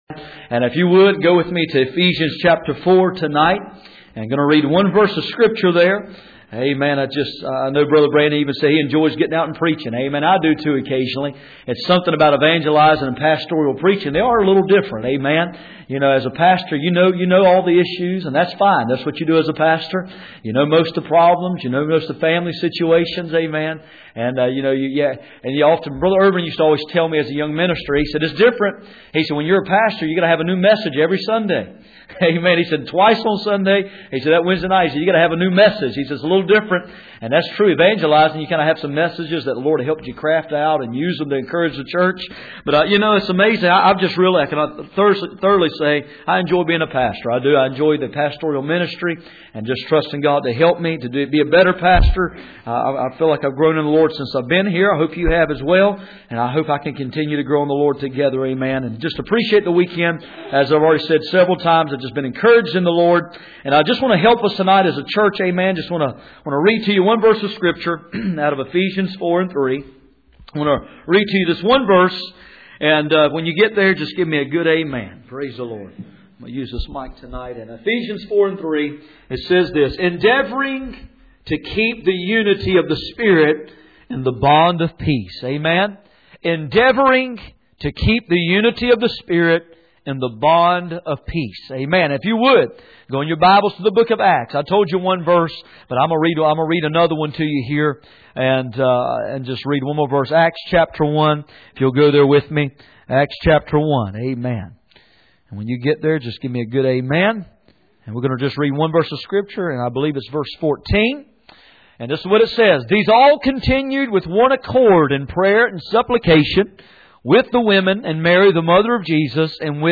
None Passage: Ephesians 4:3, Acts 1:14-2:47 Service Type: Sunday Evening %todo_render% « Let Hushai go!